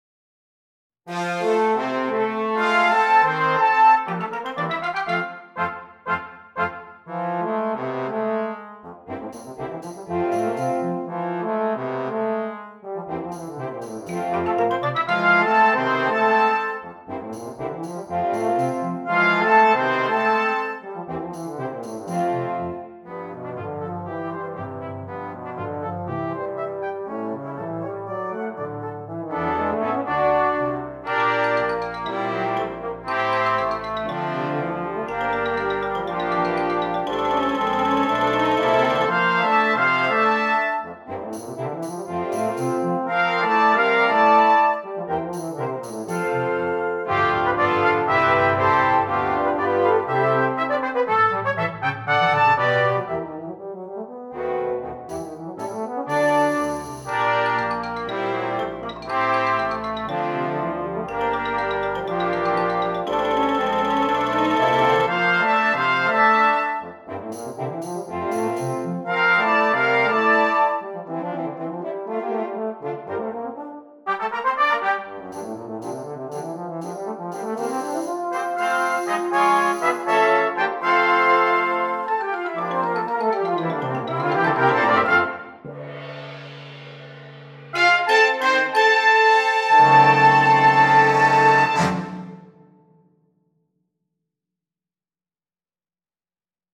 Brass Choir (4.4.3.1.1.perc)